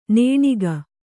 ♪ nēṇiga